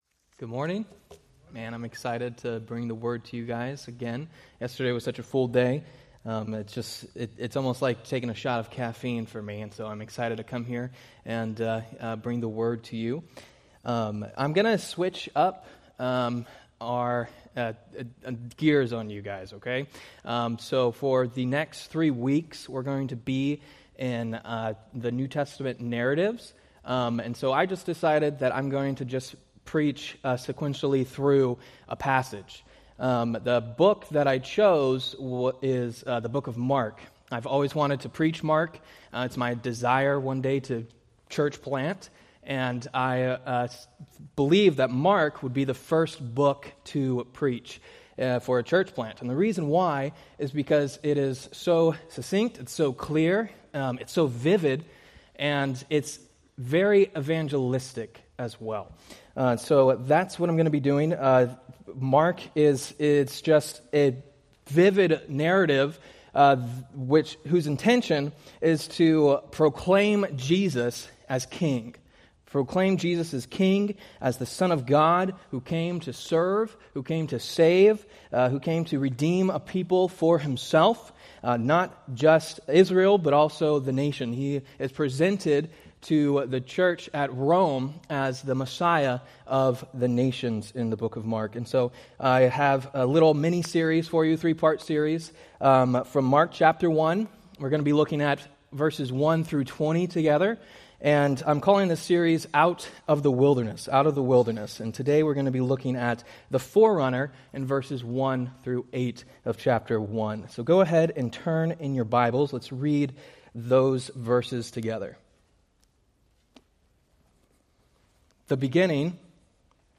Date: Sep 28, 2025 Series: Various Sunday School Grouping: Sunday School (Adult) More: Download MP3 | YouTube